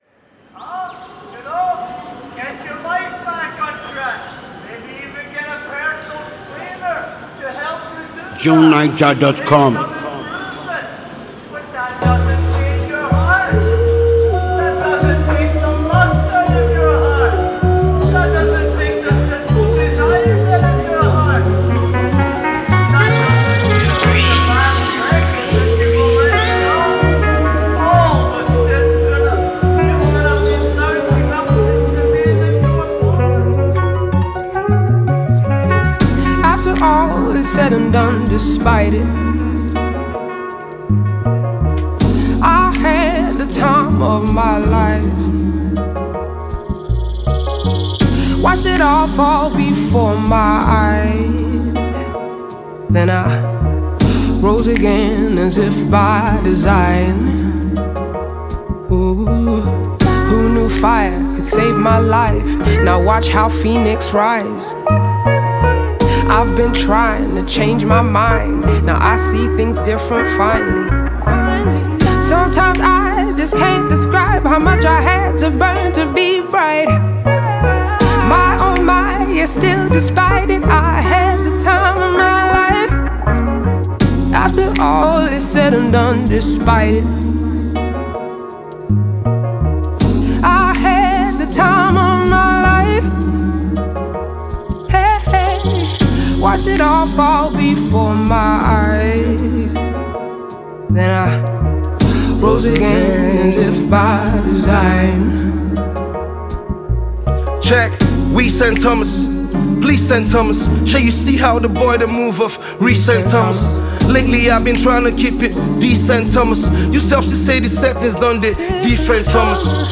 a naturally gifted Nigerian singer